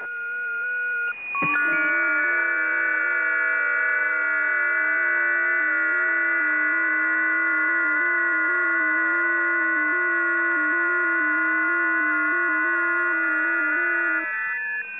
websdr_test2.wav